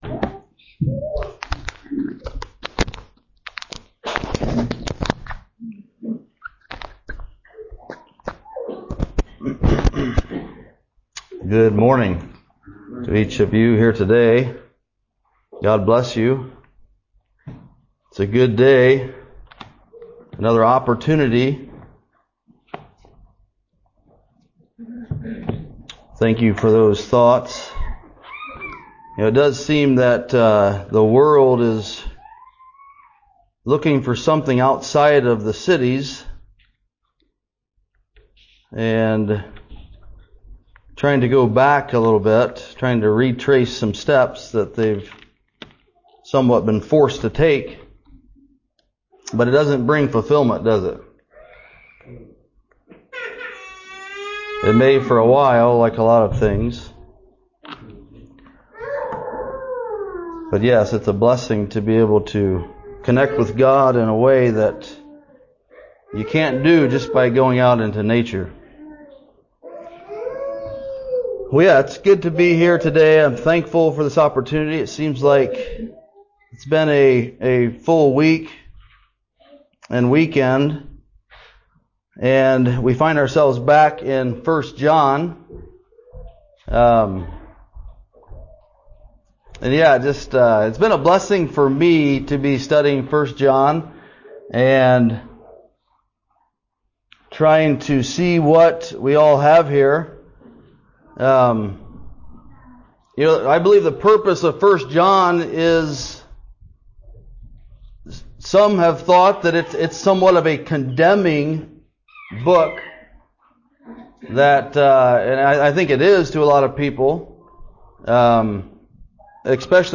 2026 Sermons - Shelbyville Christian Fellowship